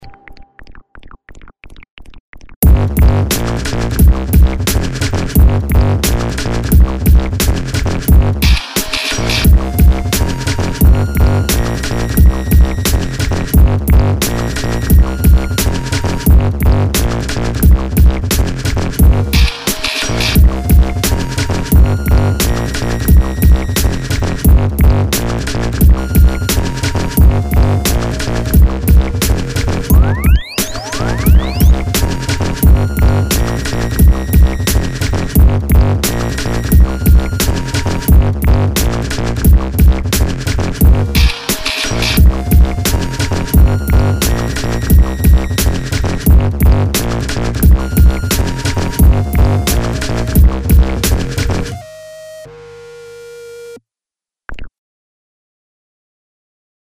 내장 힙합 | 리드머 - 대한민국 힙합/알앤비 미디어
작명 센스가 ㅋㅋㅋ 좋네요 제대로 붐붐 거리는 사운드가 특히 인상적이에요 제대로 된 지식을 갖고 있지 않아서 뭐가 어떻게 좋은 지는 함부로 못 말하겠어요 ㅠㅠ 랩하고 싶어지는 비트입니다 ^^ « Prev List Next »